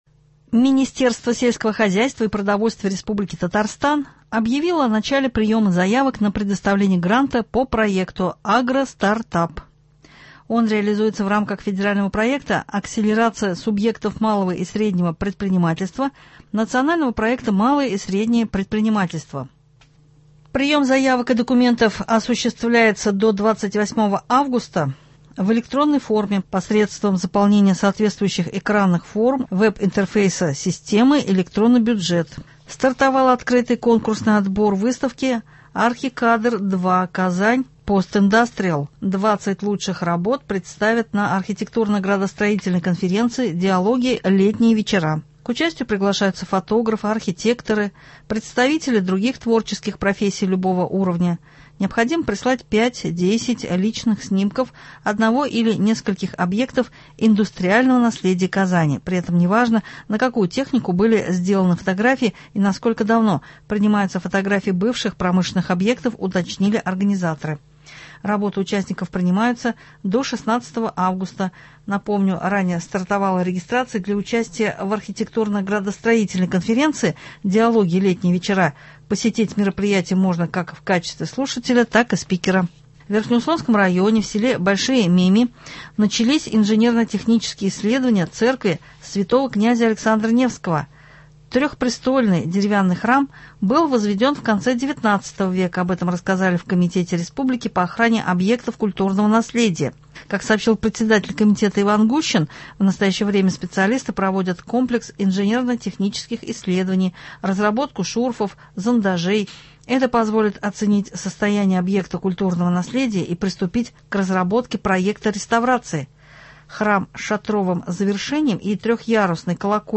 Новости (05.08.24)